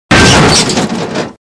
Metal crash
cartoon crash explosion sound effect free sound royalty free Movies & TV